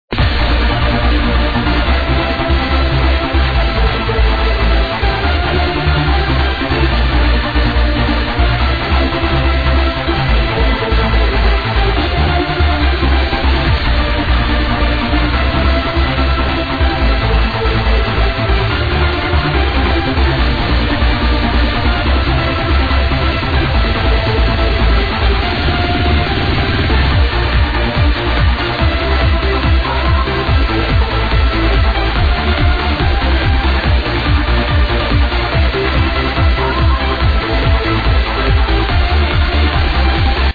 40 second loop
24kbps/22050khz/mono